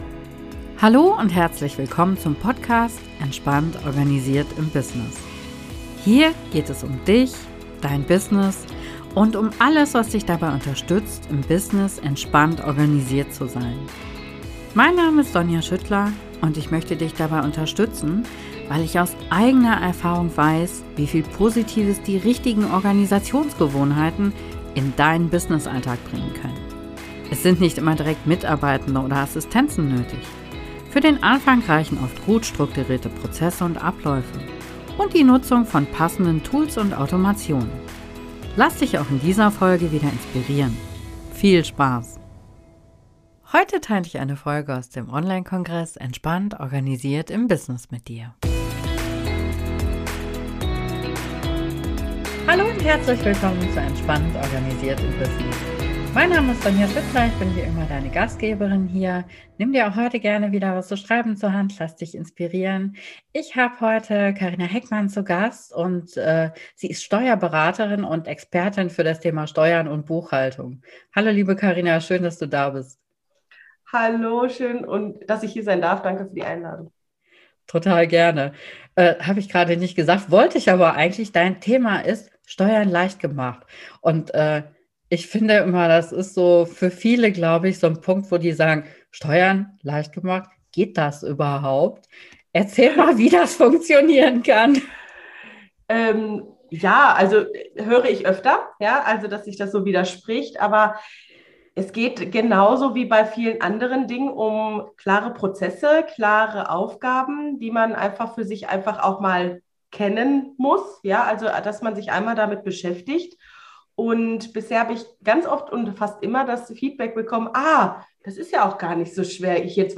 Interview-Folge